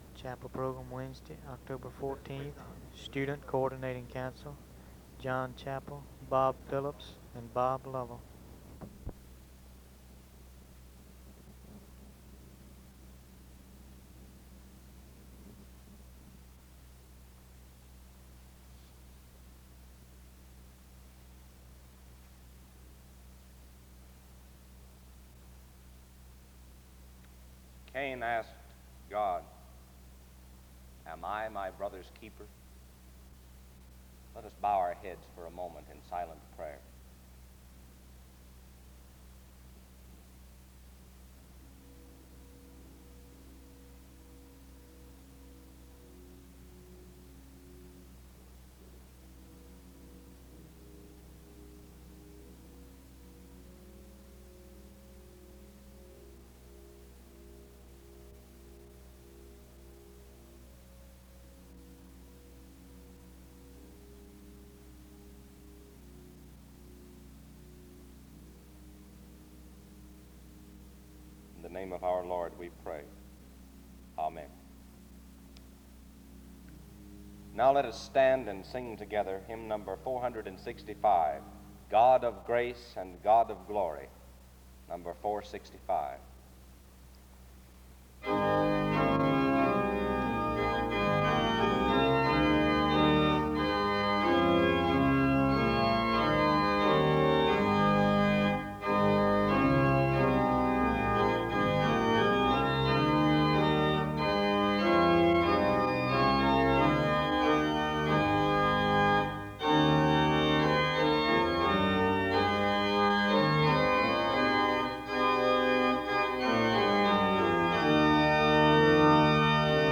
File Set | SEBTS_Chapel_Student_Coordinating_Council_1964-10-14.wav | ID: d0938cf9-aa8a-48e6-9049-7b26348caf3f | Archives & Special Collections at Southeastern